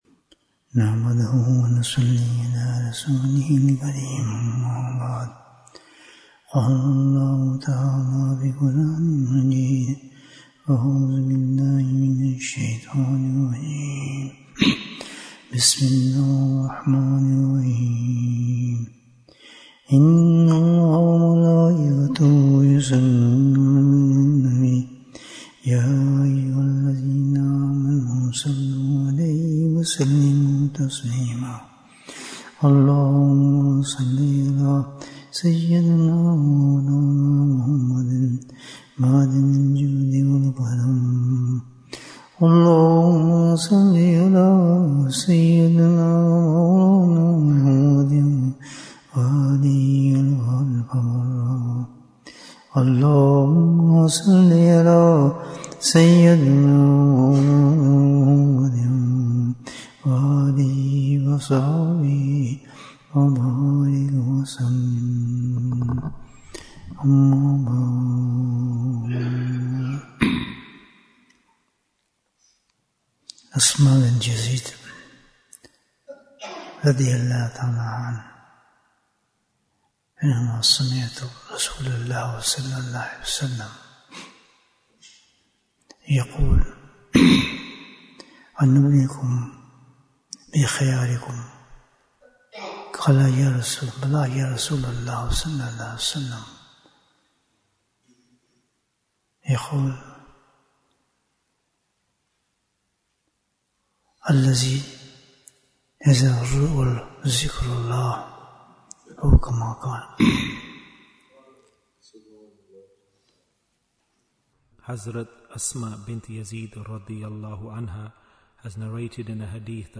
Who are the Best? Bayan, 60 minutes23rd October, 2025